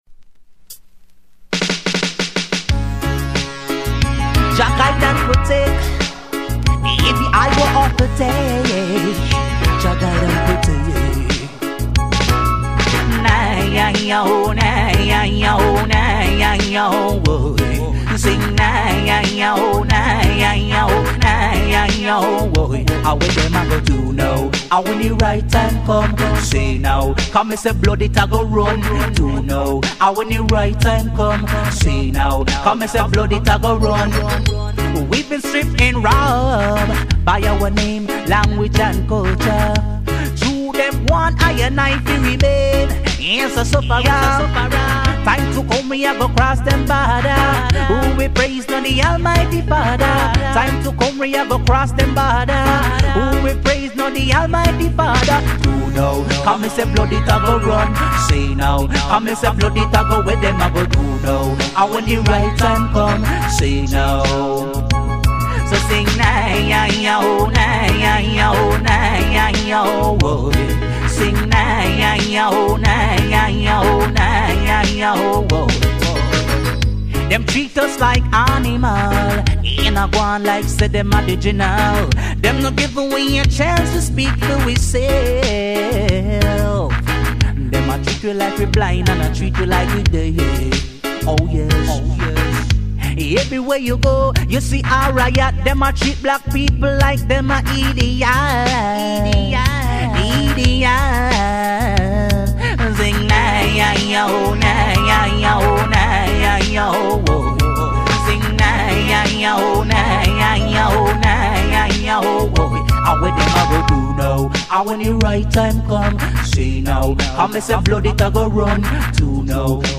digital & mad dubs !!